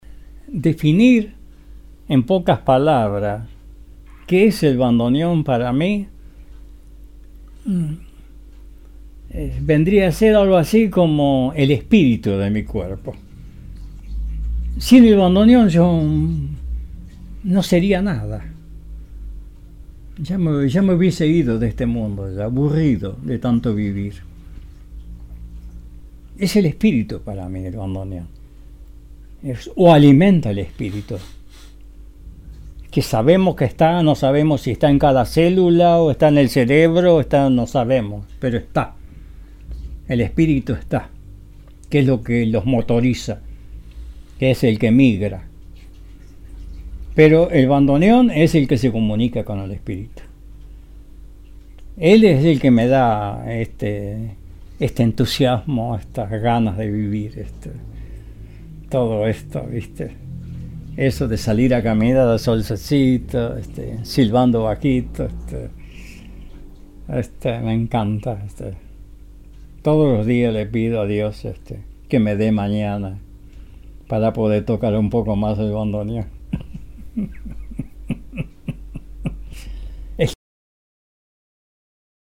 Hoy en día sigue tocando el bandoneón y dando clases a bandoneonistas.